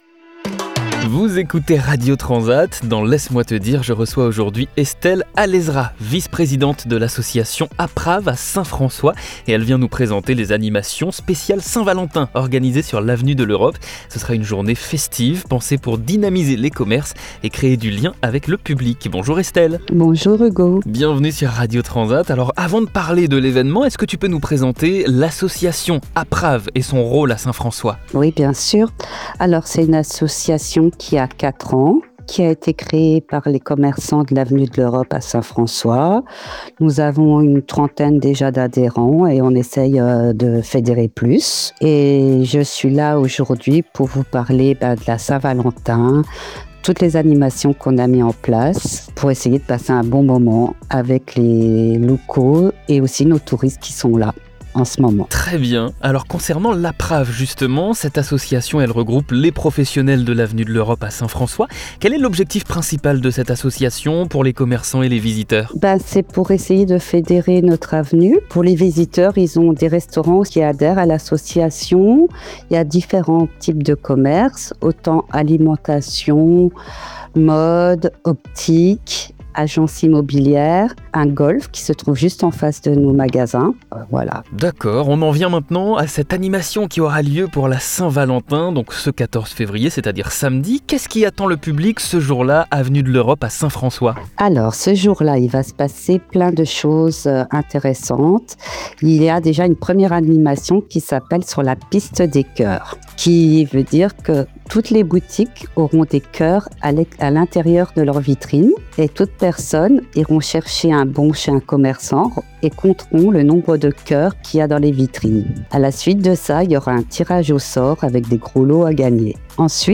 Dans cette interview